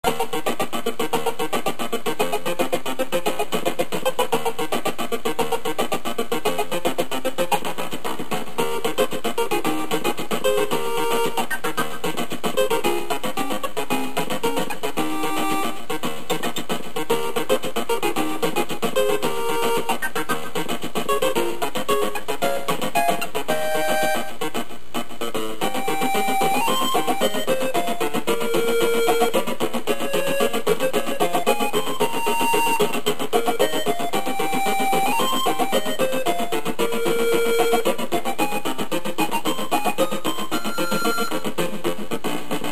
They are now in mono sound at 32khz, 64kbps in .mp3 format.